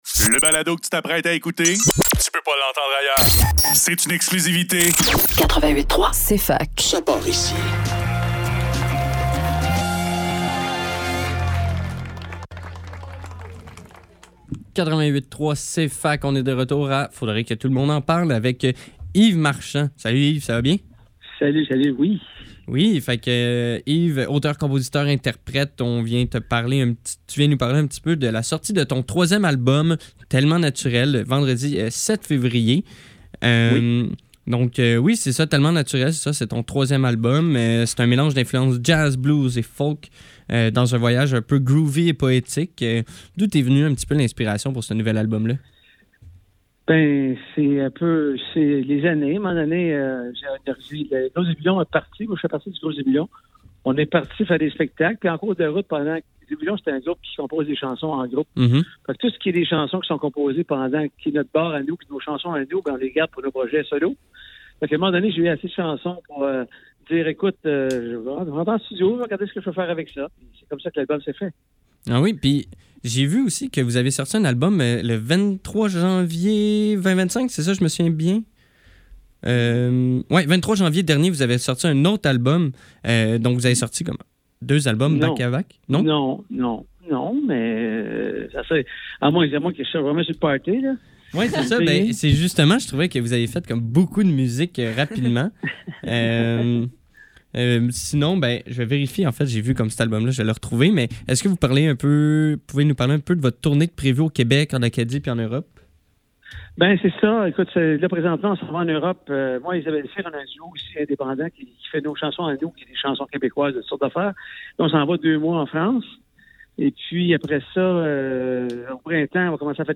Faudrait que tout l'monde en parle - Entrevue